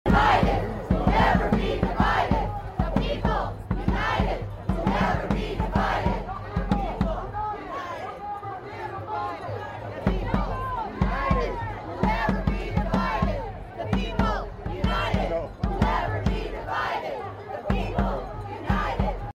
MayDay Protest In Phoenix.